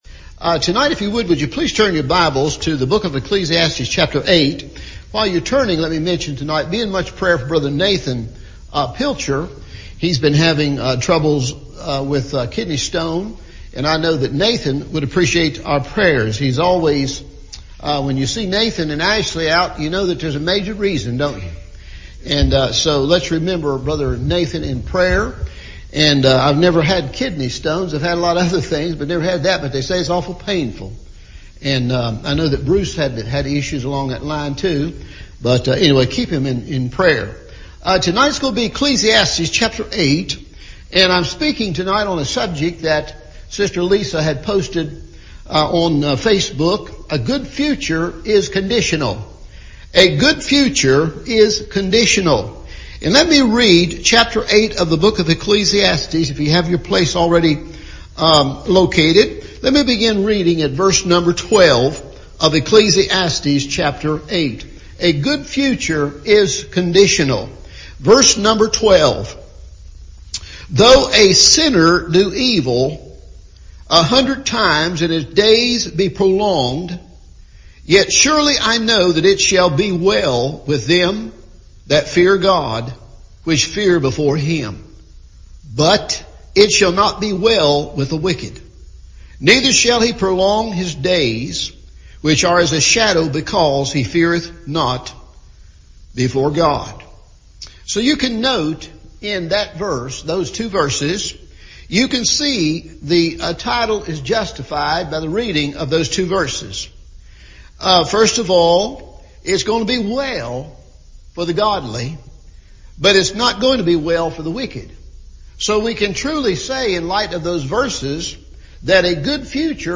A Good Future is Conditional – Evening Service